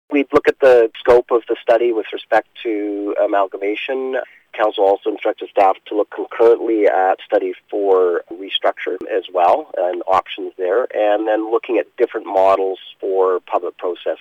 Duncan mayor Phil Kent says the issue was brought up at a recent Council meeting.